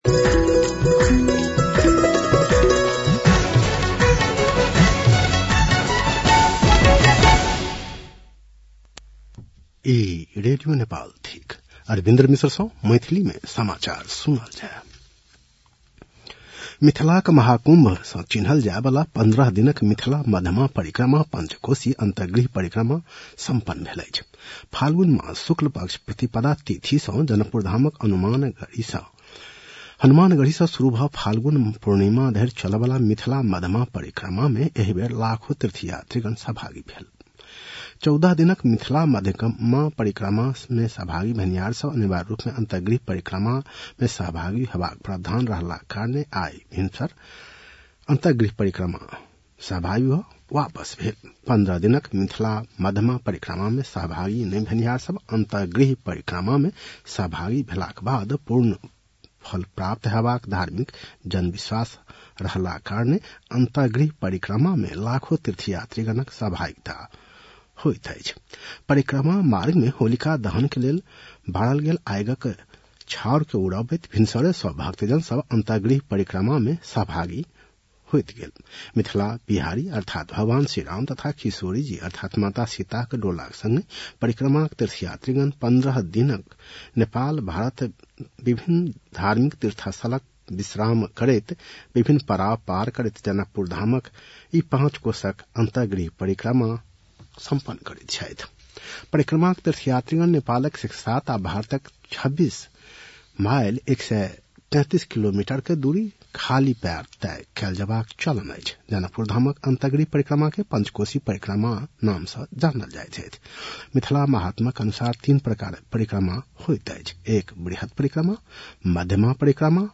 मैथिली भाषामा समाचार : १ चैत , २०८१